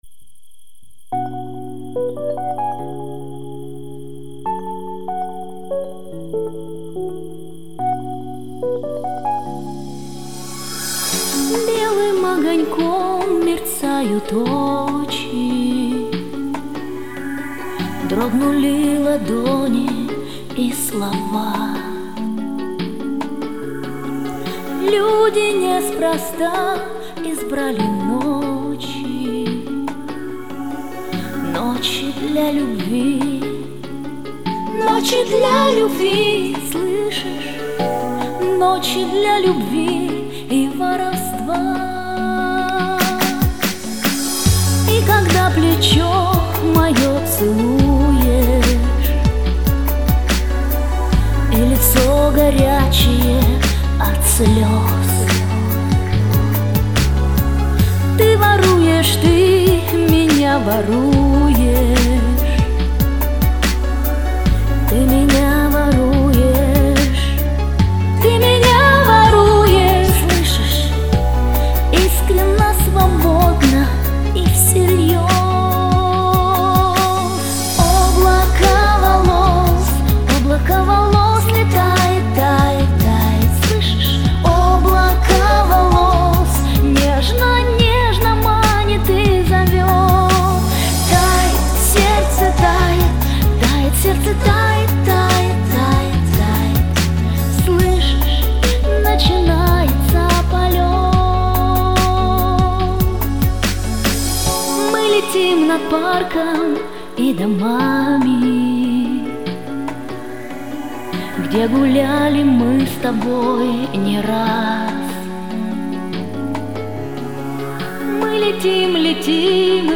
минус
варианты мужского и женского исполнения песни